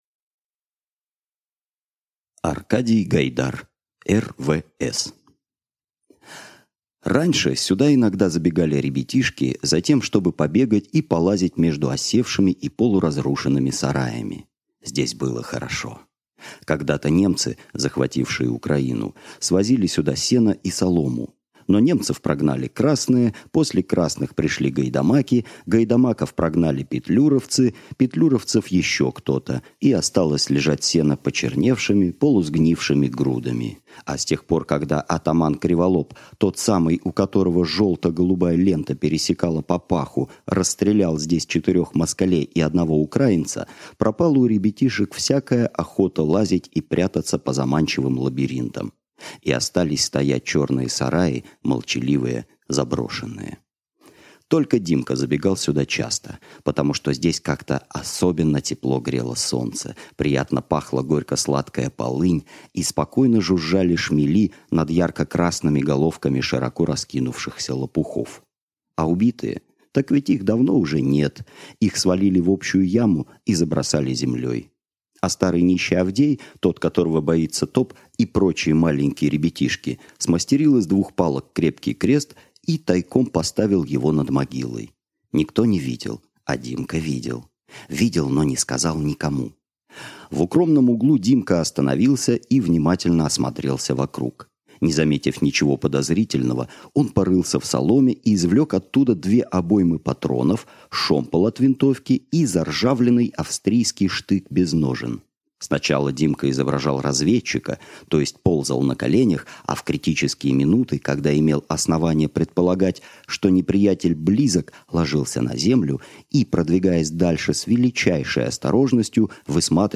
Аудиокнига Р.В.С. | Библиотека аудиокниг